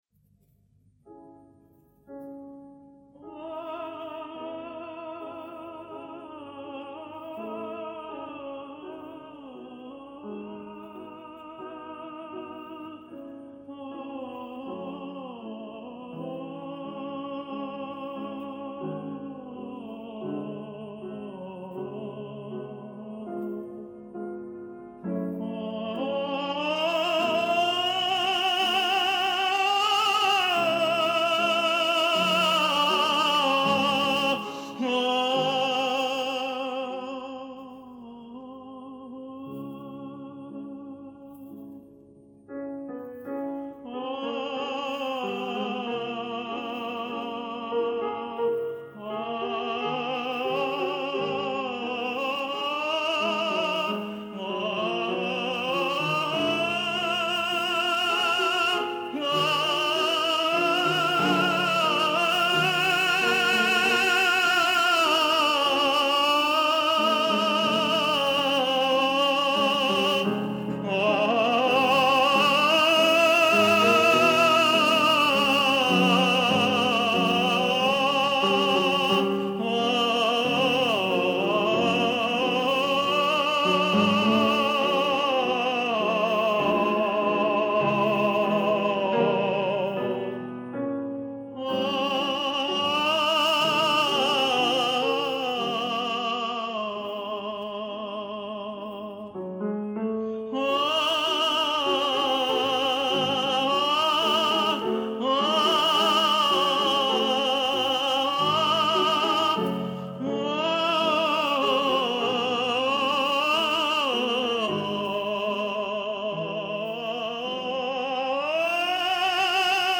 Да, оба прекрасных тенора! но я наверное больше отношусь к «лемешисткам»!